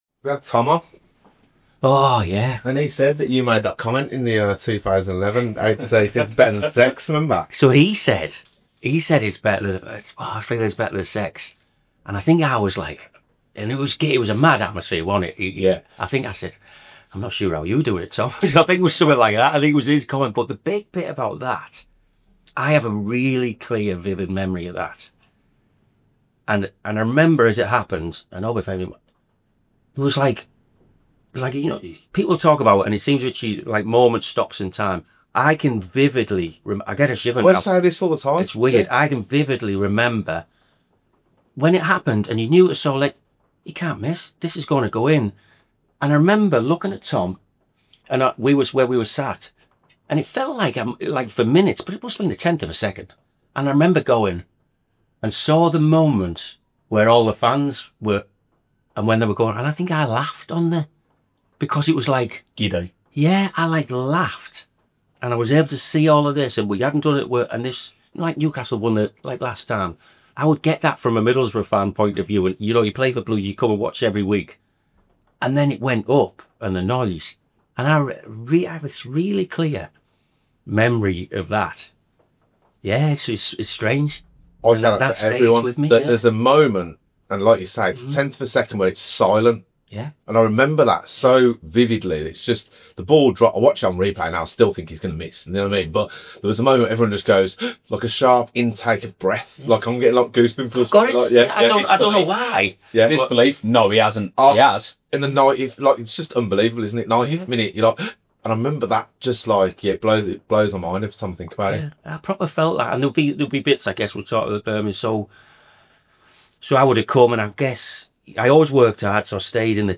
Jon McCarthy — former Birmingham City winger, cult hero and Northern Ireland international — sits down for a deep, honest and heartfelt interview.